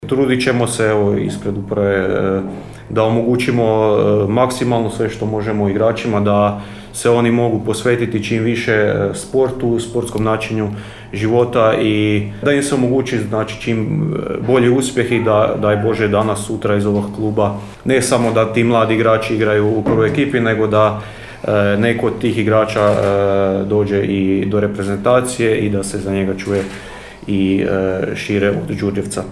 Nova sezona u Trećoj nogometnoj ligi Sjever samo što nije počela, a u Nogometnom klubu Graničar iz Đurđevca veliki su ciljevi, ambicije i planovi. Kako je to istaknuto na konferenciji za medije koju je organizirao klub, cilj seniorske ekipe će od prvog kola biti baciti se na glavu u želji da Graničar napadne vrh prvenstvene ljestvice i osvoji ligu, pa i preseli u viši rang.